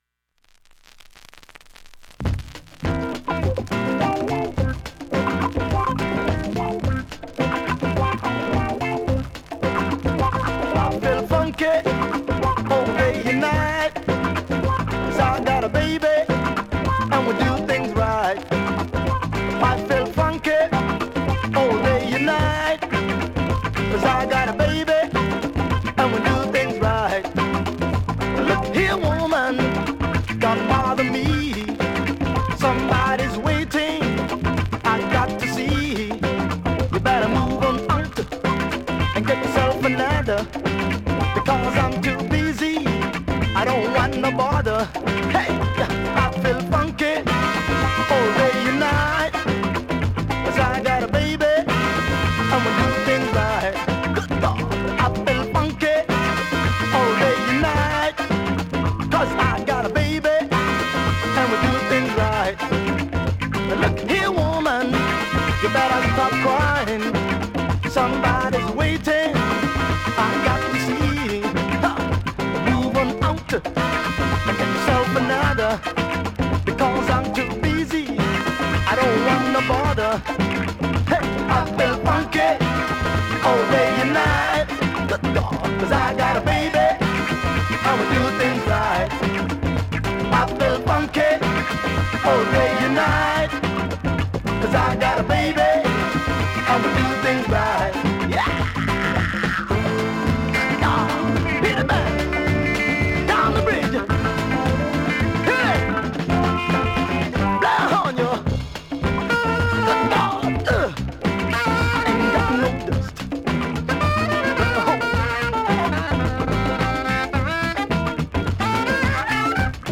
◆薄いスレ多いですが音質は悪くは無い感じです